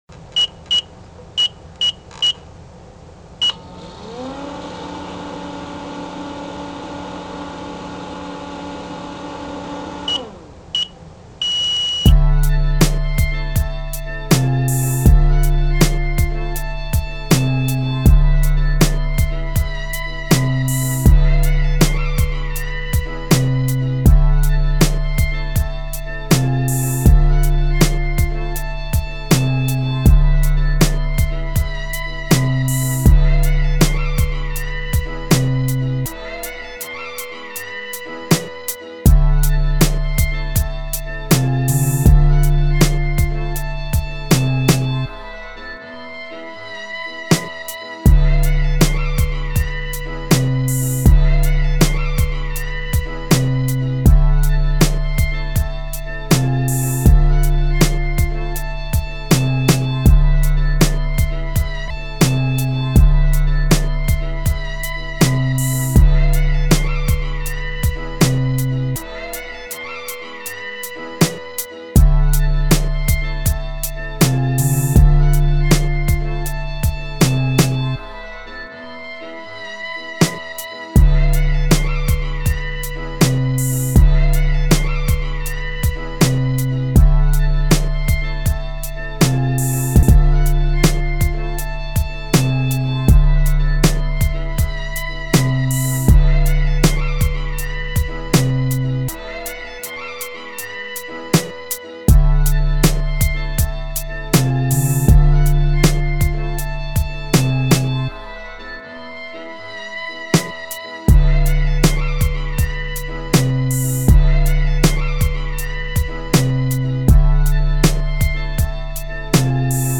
это десять лучших сочных битков подземного стиля.
Это минуса для подземного(андерграунд хип-хопчика).
Обычно они спокойные, не имеют ломанного ритма.